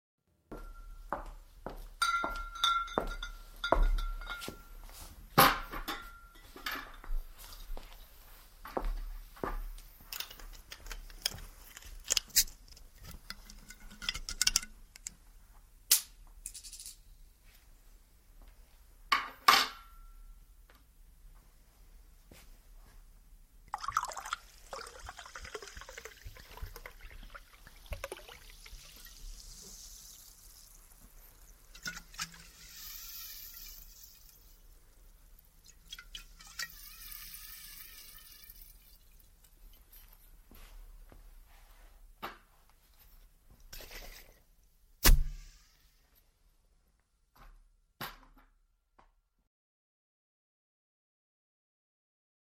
3D spatial surround sound "A bottle of soda"
3D Spatial Sounds